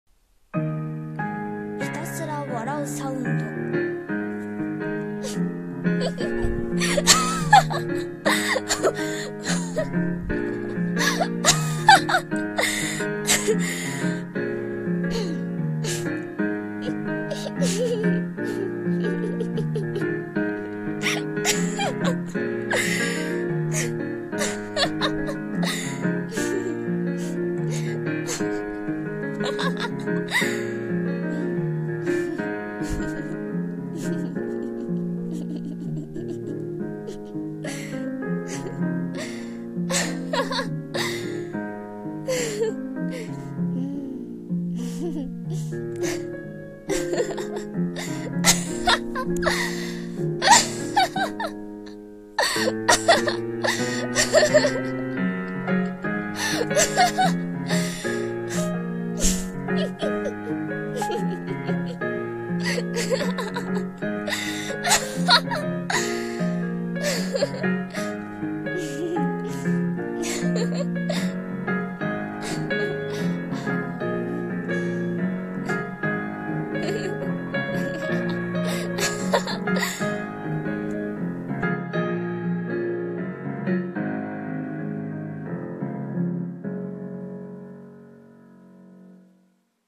ひたすら笑うサウンド